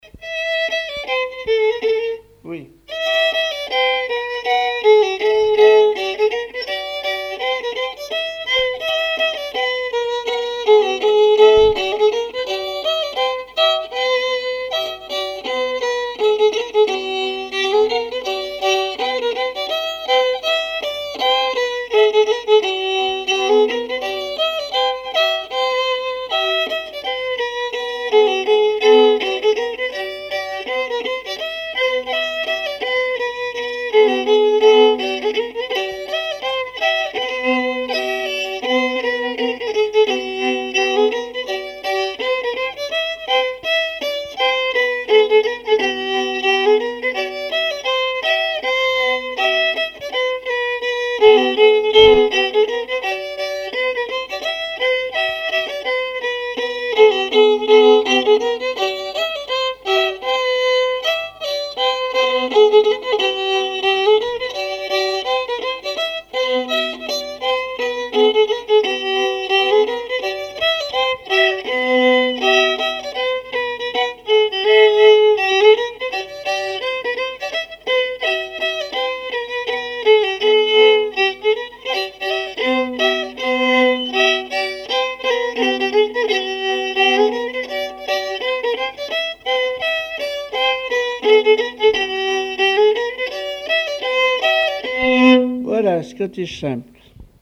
danse : scottich trois pas
Conservatoire des Musiques Anciennes et Traditionnelles de Vendée - Arexcpo en Vendée
répertoire musical au violon
Pièce musicale inédite